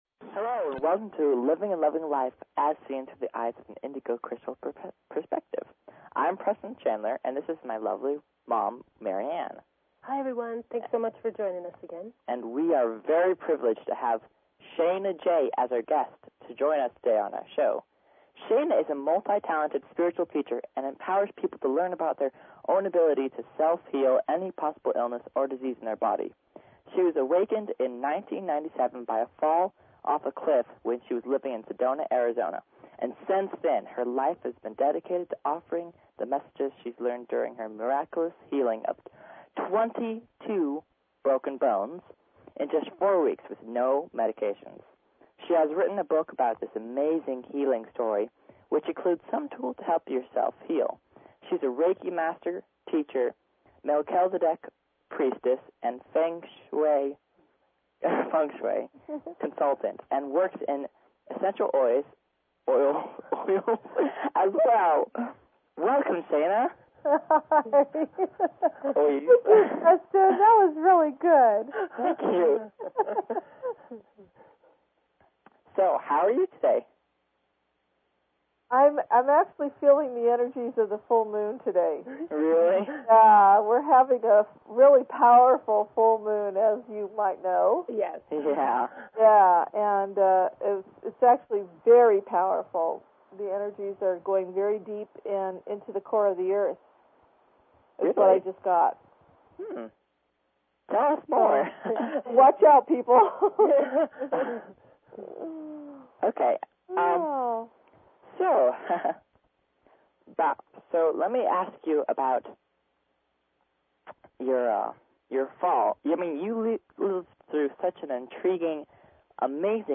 Talk Show Episode, Audio Podcast, Living_and_Loving_Life and Courtesy of BBS Radio on , show guests , about , categorized as